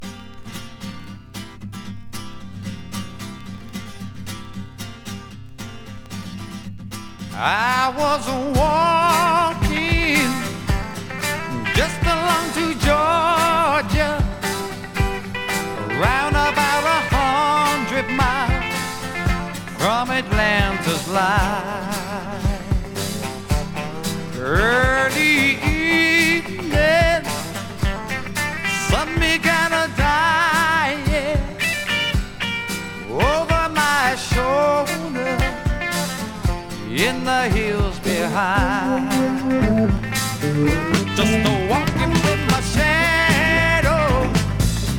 SideAでは英国の香りが漂い、ドラマチックさたっぷり。SideBはポップさとスワンプ感も。
Rock, Pop　USA　12inchレコード　33rpm　Stereo